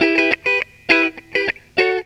GTR 56 EM.wav